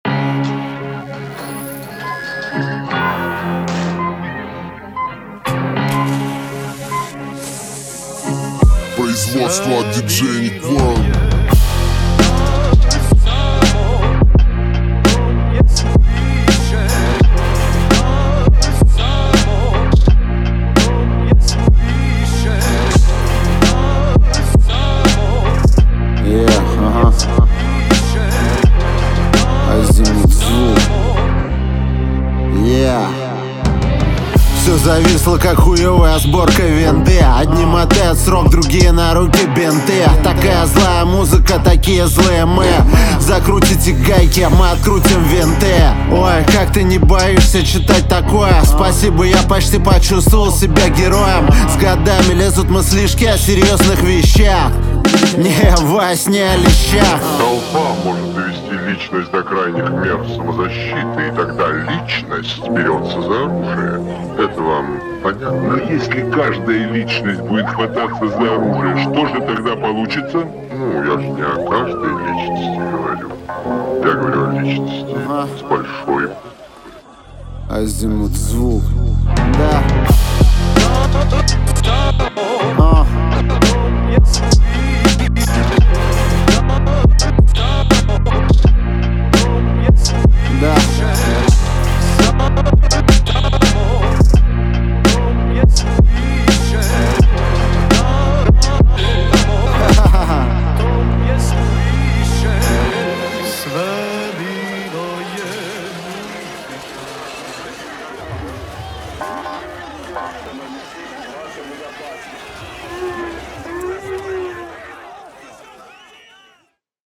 Трек размещён в разделе Рэп и хип-хоп / Русские песни.